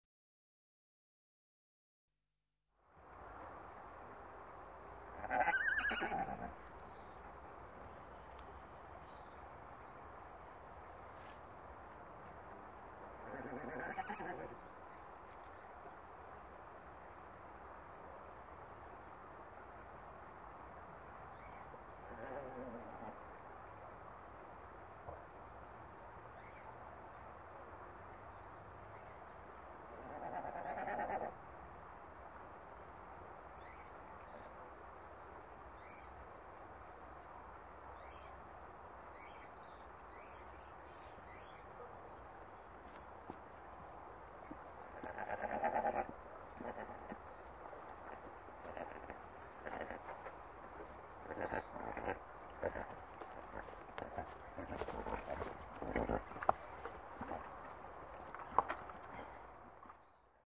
Мелодичный звук прекрасных шотландских пони